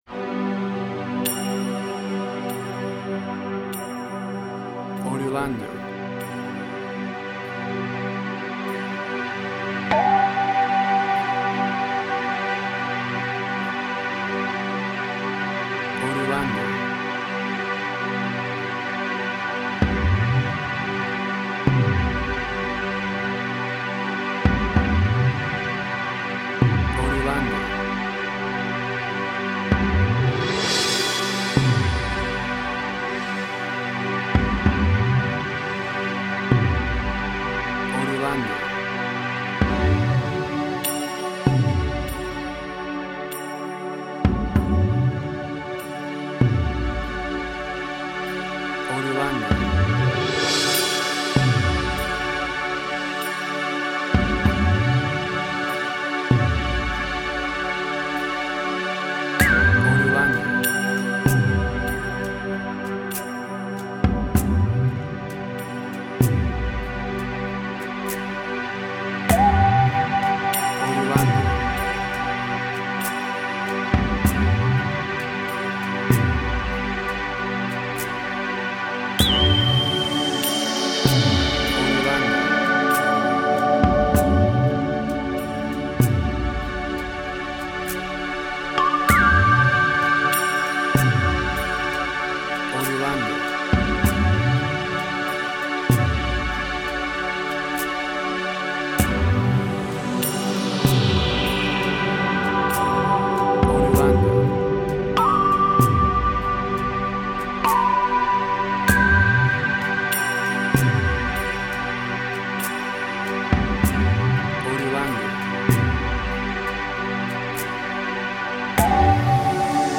New Age.
emotional music
Tempo (BPM): 50